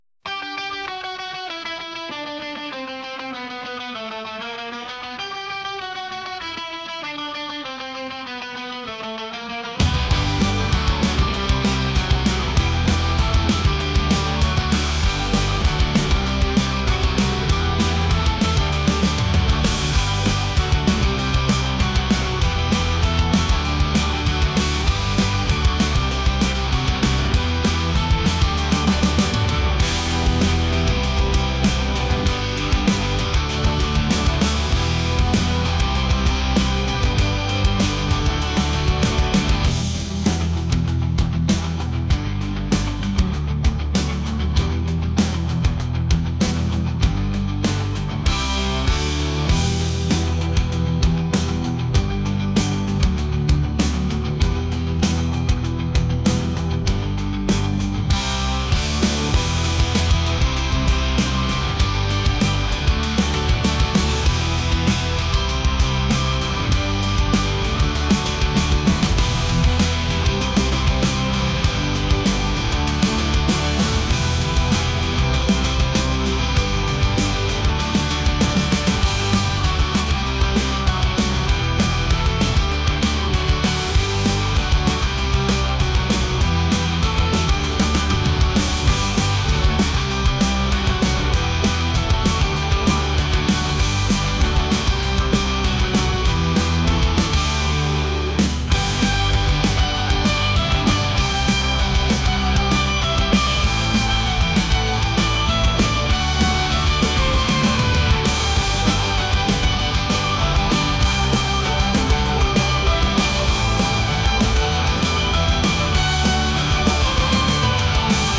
rock | alternative | indie